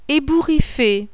Objet mondeduvivant oiseau POULE